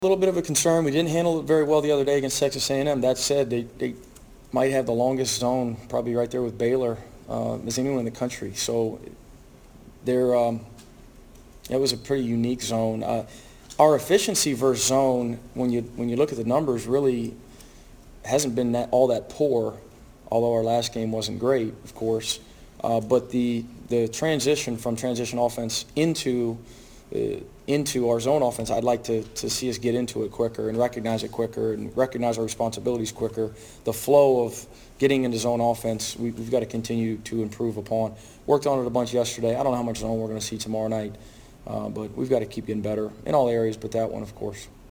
Coach White gave his opinion on how the Tigers’ try to control the tempo of the game.